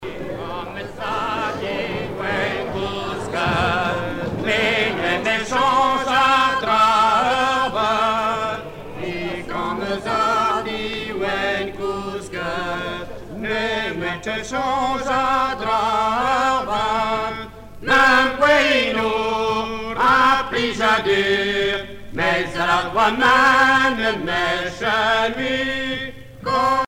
Fonction d'après l'analyste gestuel : à marcher
Genre strophique